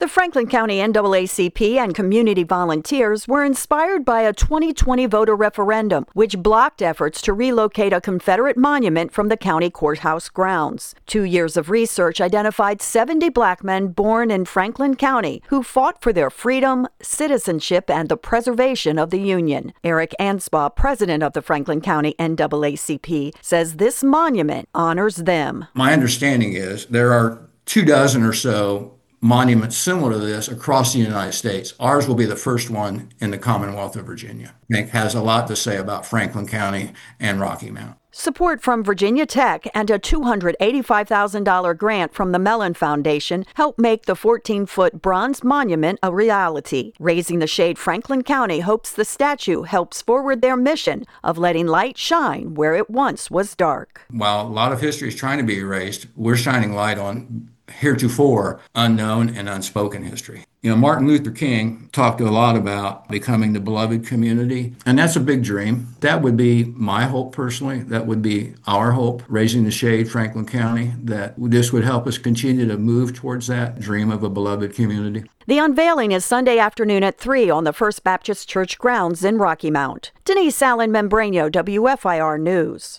1-16-Statue-Unveiling-wrap-1.mp3